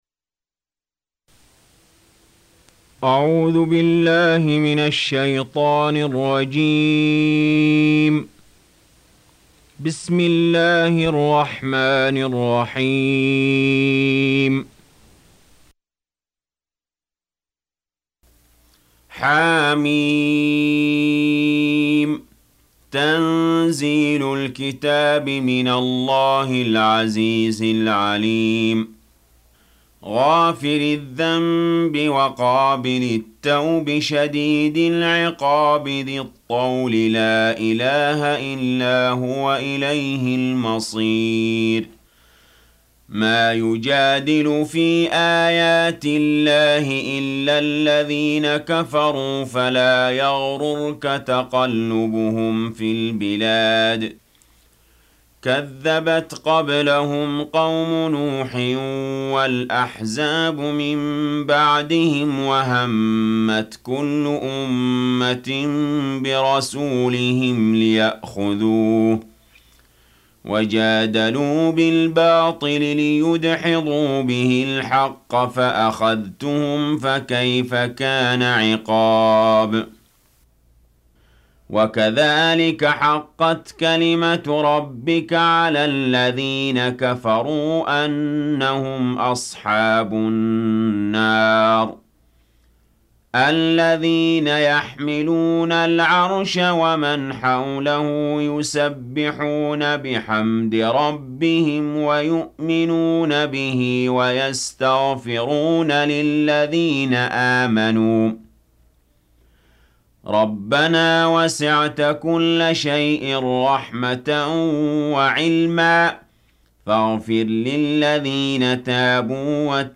Surah Sequence تتابع السورة Download Surah حمّل السورة Reciting Murattalah Audio for 40. Surah Gh�fir سورة غافر N.B *Surah Includes Al-Basmalah Reciters Sequents تتابع التلاوات Reciters Repeats تكرار التلاوات